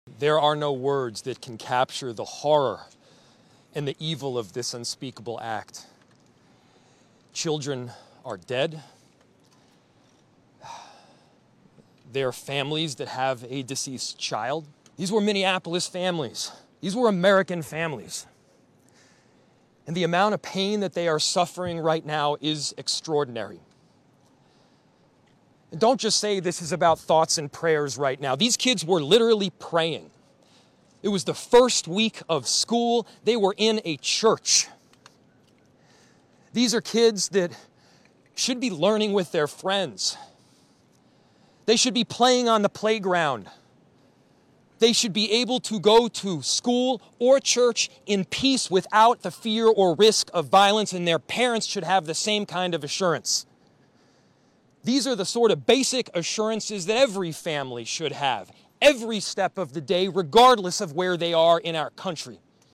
Minneapolis mayor Jacob Frey addressed sound effects free download By cnn 572 Downloads 18 hours ago 62 seconds cnn Sound Effects About Minneapolis mayor Jacob Frey addressed Mp3 Sound Effect Minneapolis mayor Jacob Frey addressed the community after a deadly shooting at a local Catholic school Wednesday. The mayor urged the community to support the grieving families.